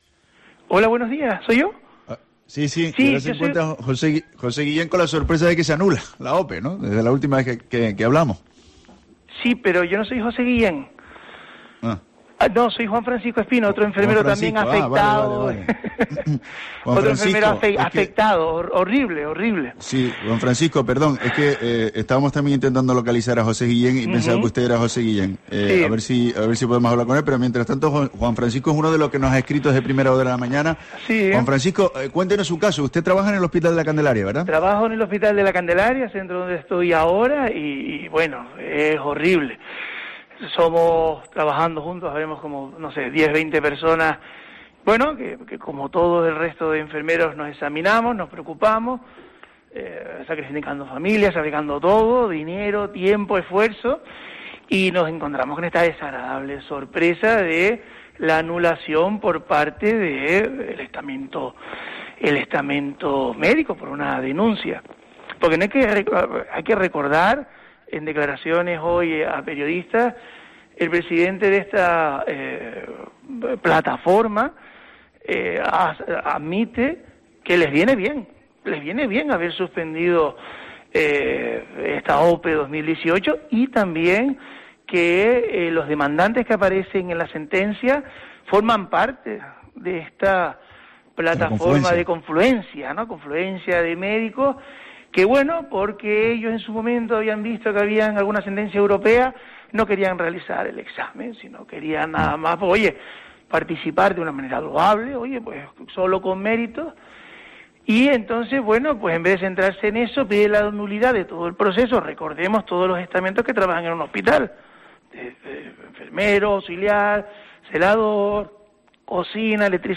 Entrevista sanitarios afectados anulación de la OPE